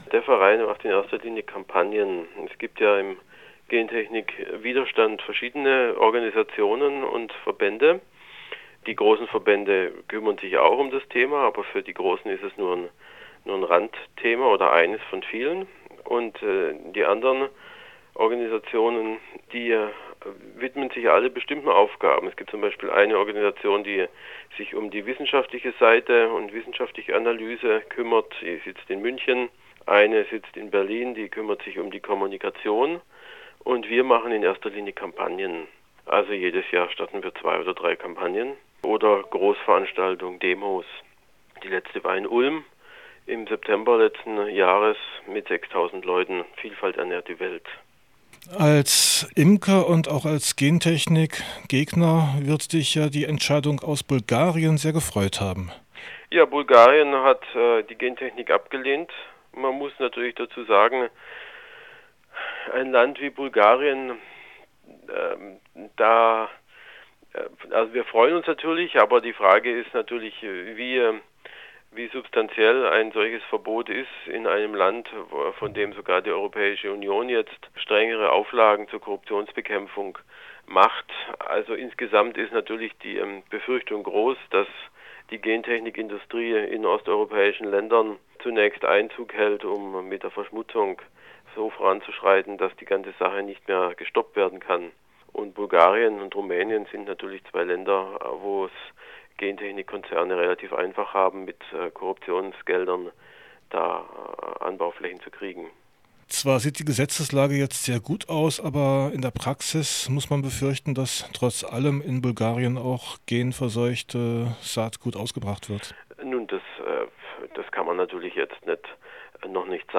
Telefonat